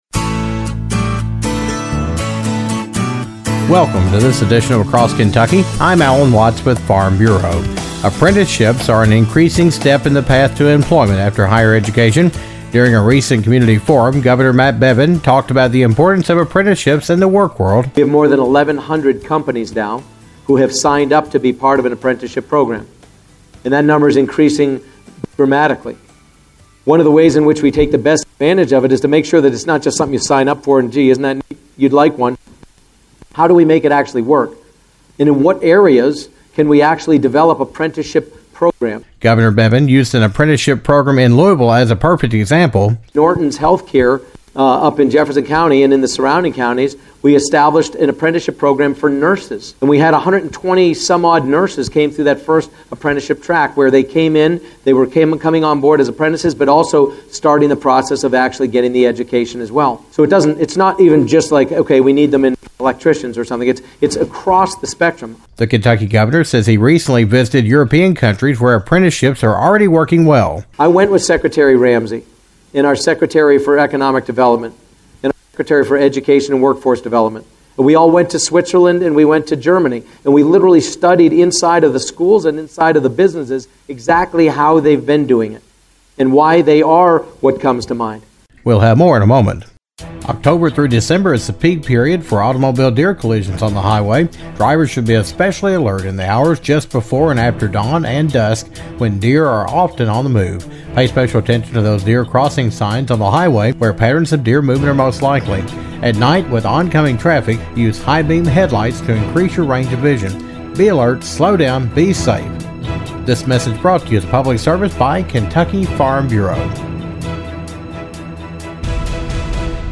Apprenticeships are the wave of the future as people prepare to enter the workforce. Kentucky Governor Matt Bevin discusses the importance of apprenticeships, who is doing them and his goal for the state to become the apprentice capital of the world.